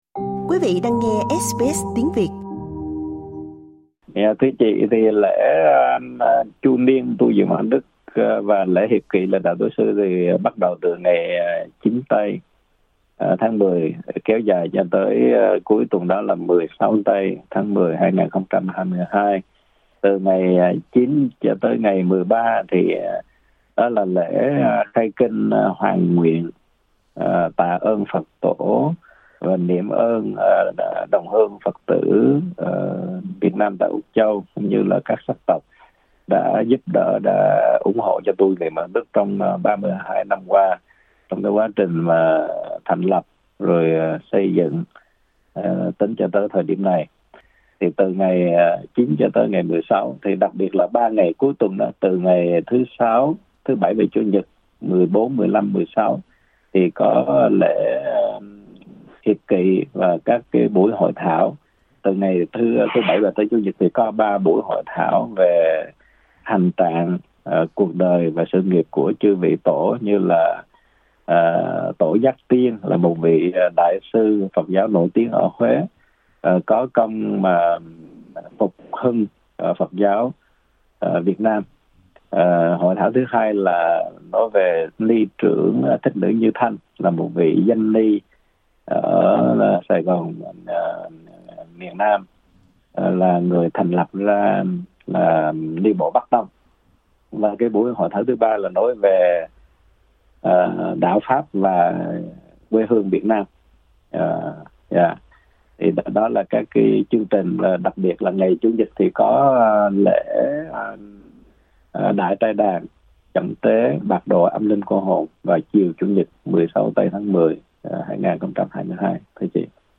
Trò chuyện với SBS